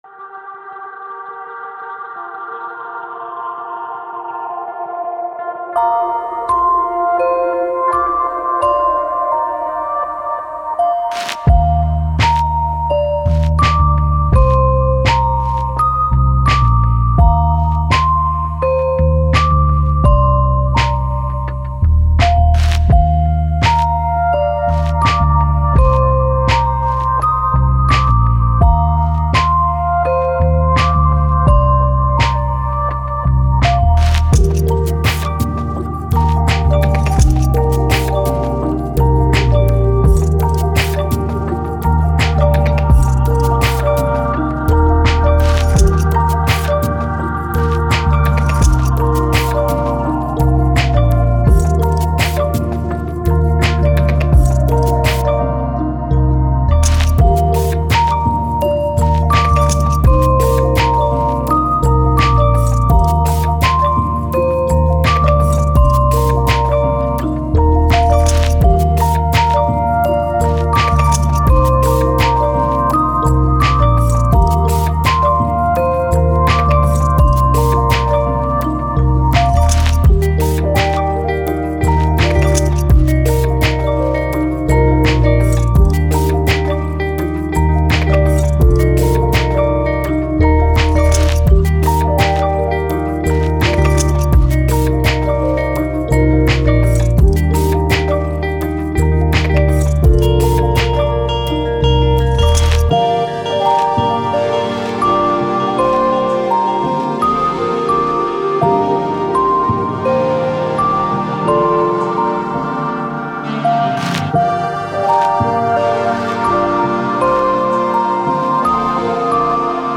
Ambient, Lofi, Electronic